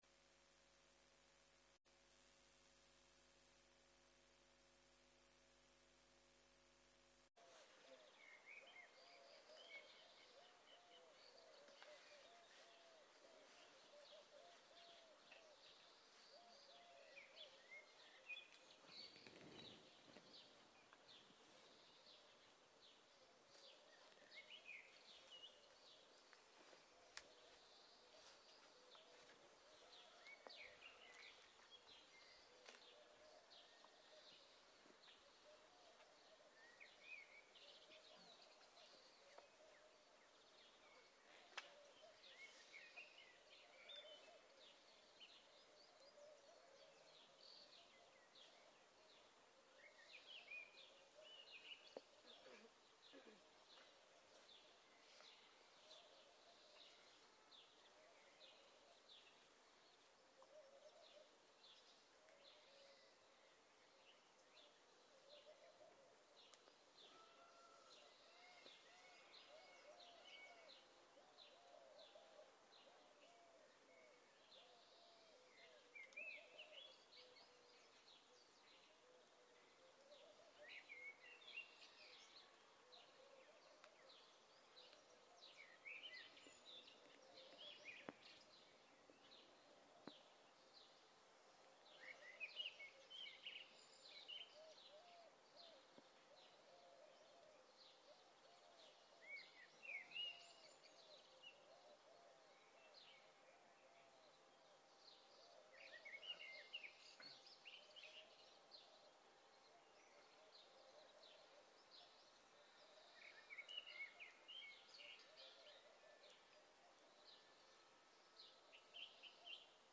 Guided meditation